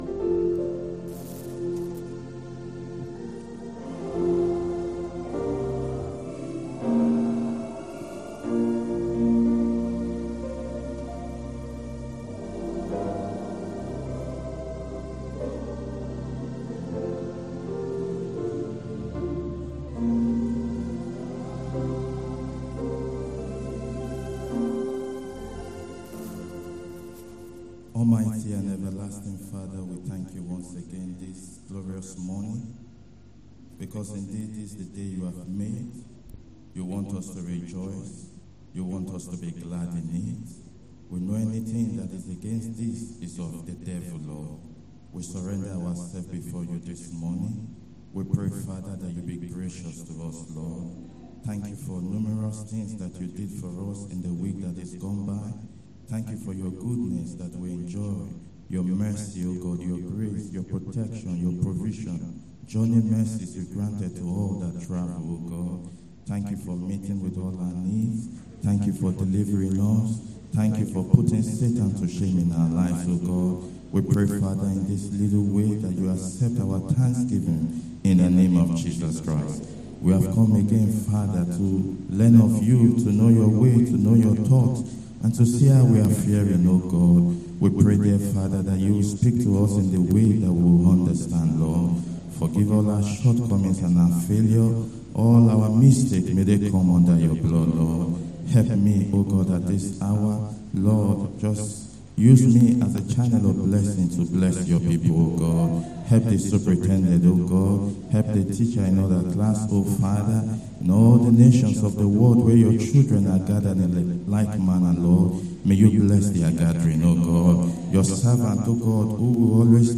Sunday School 27/10/24